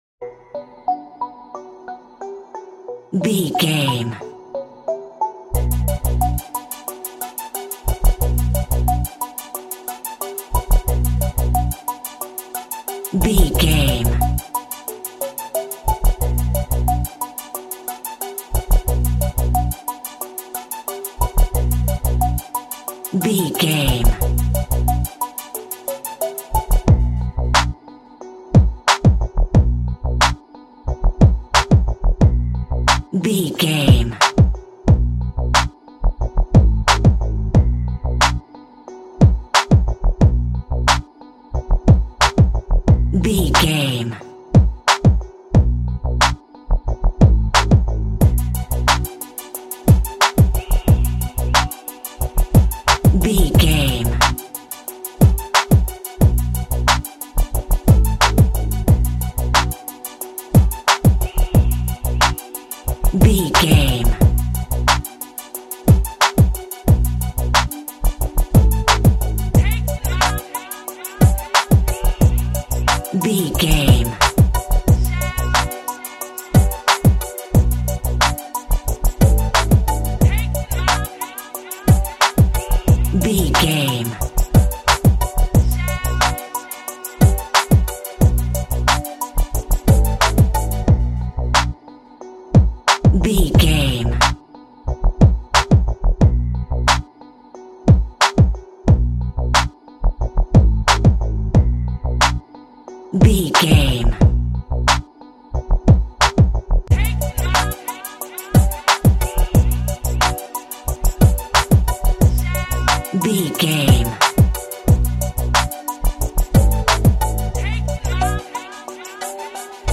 Ionian/Major
aggressive
disturbing
foreboding
hypnotic
synthesiser
bass guitar
drum machine
vocals